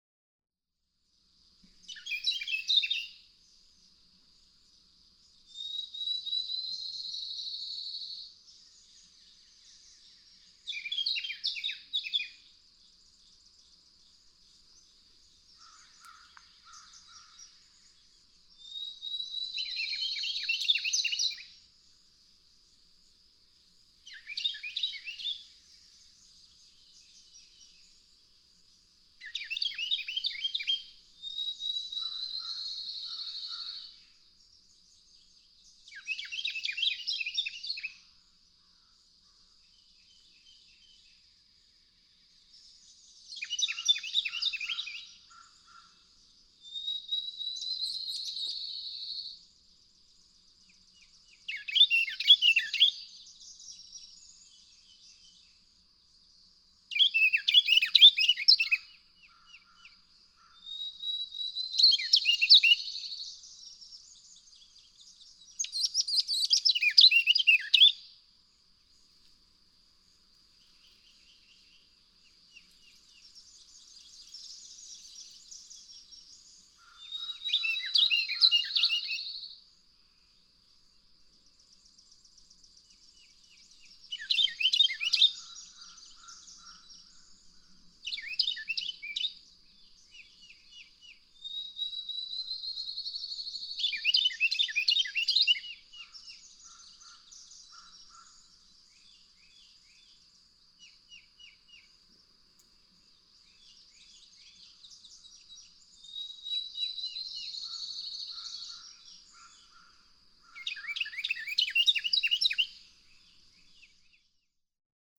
Carolina wren
Plastic song, probably by a young male hatched earlier in the year.
Bay Creek Wilderness, Shawnee National Forest, Eddyville, Illinois.
185_Carolina_Wren.mp3